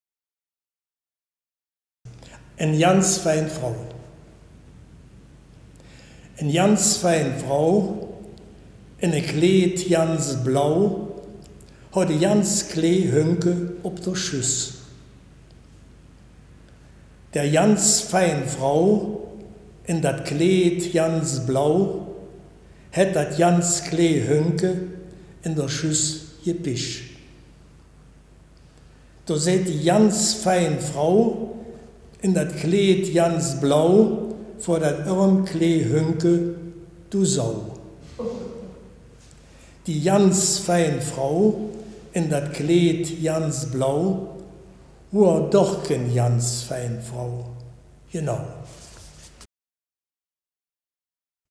Baaler Riedelland - Erkelenzer Börde
Gedicht